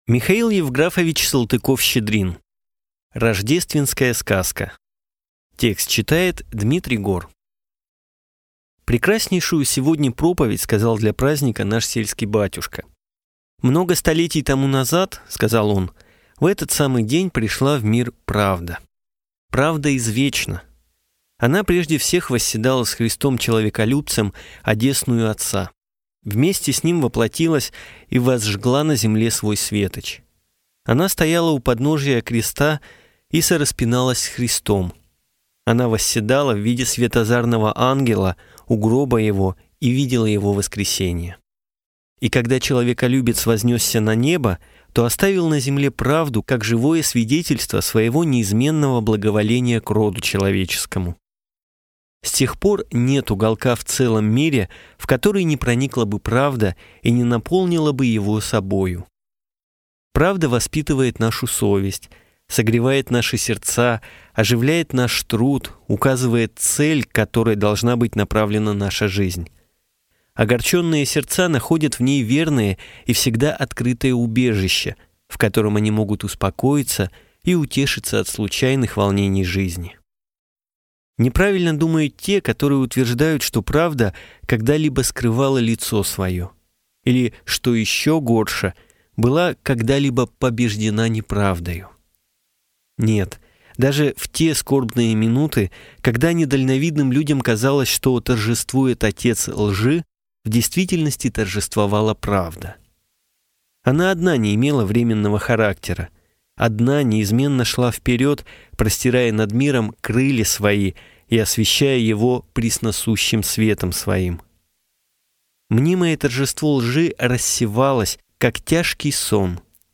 Аудиокнига Рождественская сказка